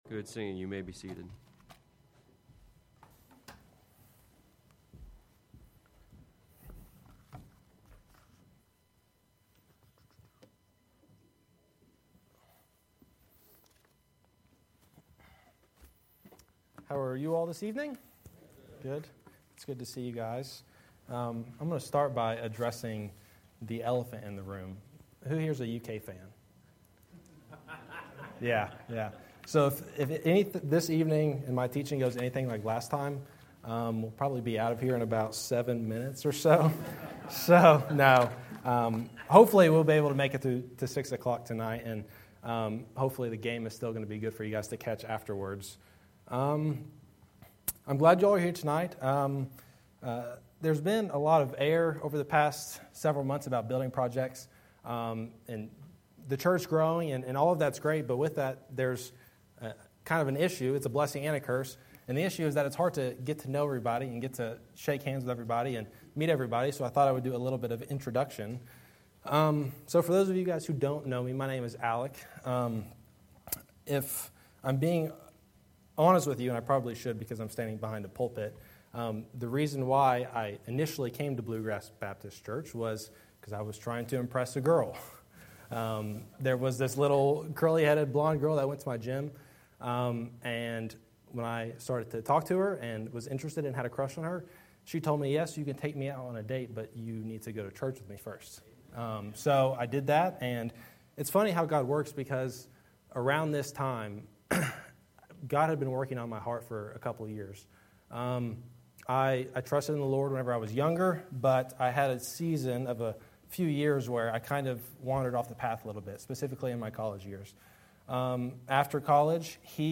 Sermons by Bluegrass Baptist Church